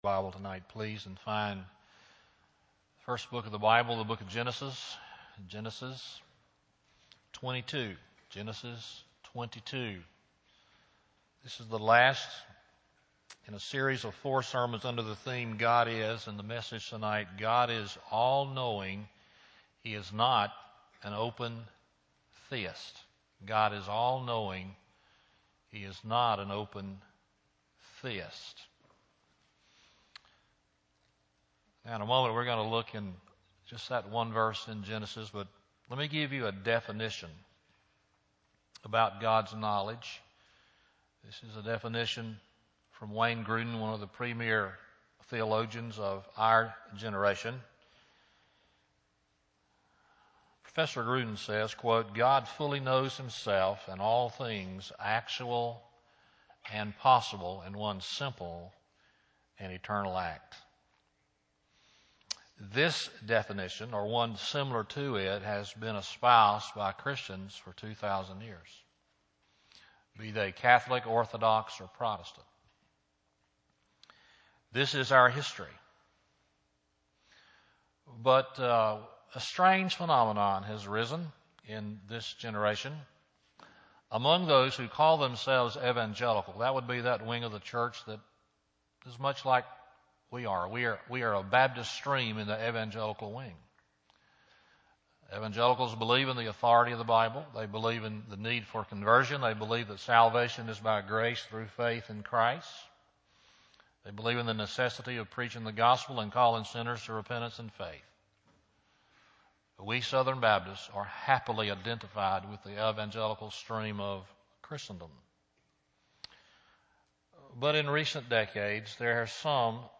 God Is All Knowing Not an Open Theist Sermon Date: September 18, 2011 PM Scripture: 1 Corinthians 2:10-11 Sermon Series: God Is .